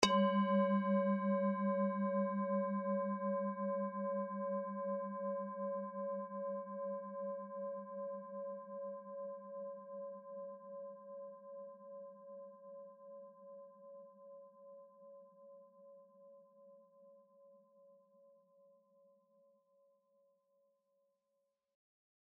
Cuenco5.mp3